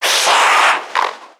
NPC_Creatures_Vocalisations_Infected [24].wav